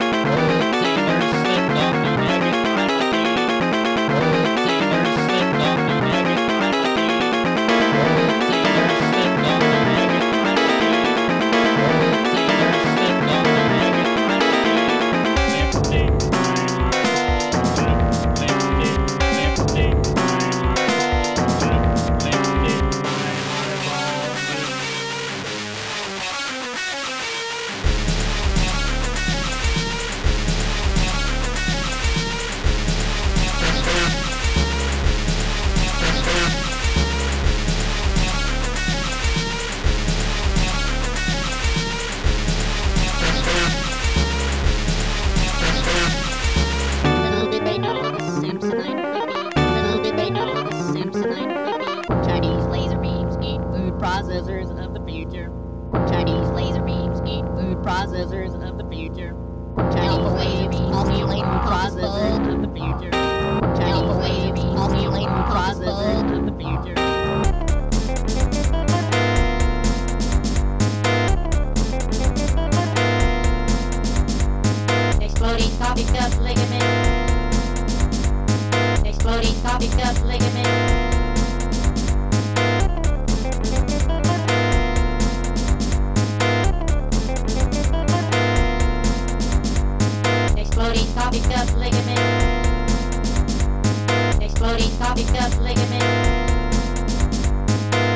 guitar4
guitchrdjazzy
HighHat2
wholetonechrd
SnreDrm2
elec. bass drum
SynthBass1